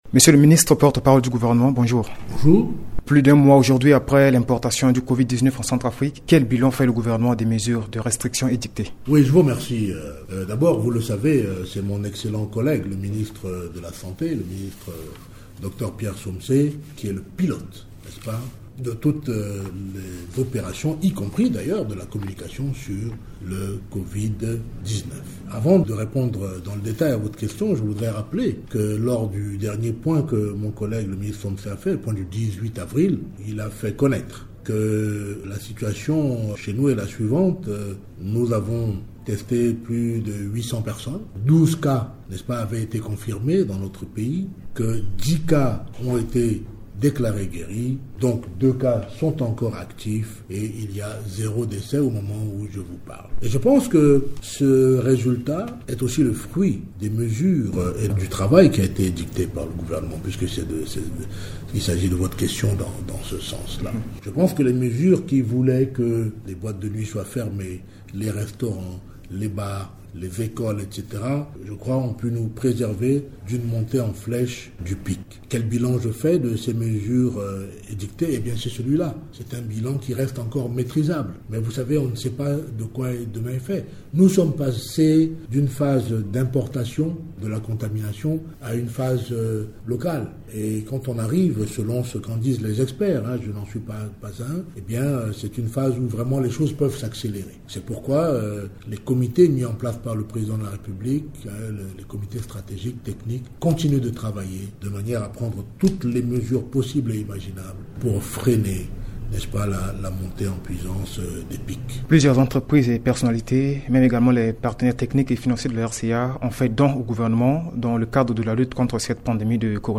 Dans un entretien accordé à Radio Ndeke Luka, Ange Maxime Kazagui, ministre de la Communication et porte-parole du Gouvernement, témoigne de l’efficacité des mesures mises en place par le gouvernement en vue d’empêcher la propagation de la pandémie de coronavirus en République Centrafricaine. Par ailleurs, il indique que les autorités gouvernementales réfléchissent à des stratégies pour faire face aux conséquences économiques de cette pandémie. L’interview a été réalisée juste après la dernière mise au point faite par le ministère de la santé sur le Covid-19.